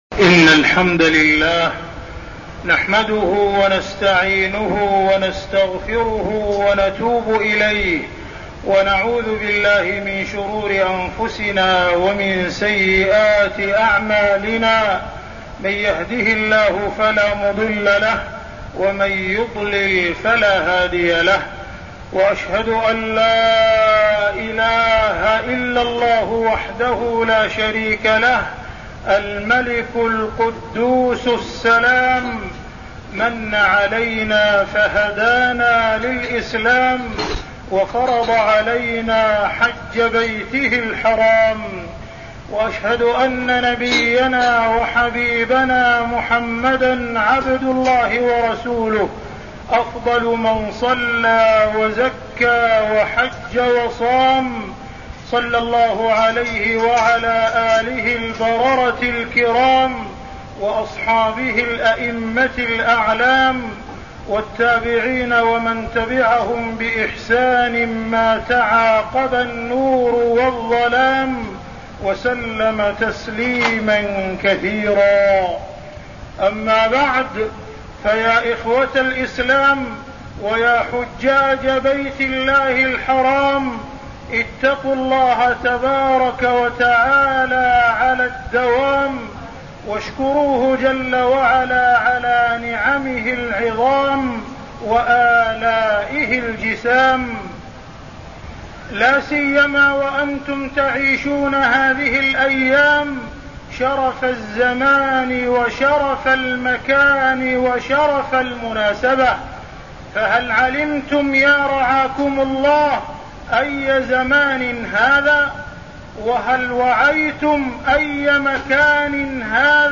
تاريخ النشر ٢٥ ذو القعدة ١٤١٤ هـ المكان: المسجد الحرام الشيخ: معالي الشيخ أ.د. عبدالرحمن بن عبدالعزيز السديس معالي الشيخ أ.د. عبدالرحمن بن عبدالعزيز السديس الحج إلى بيت الله الحرام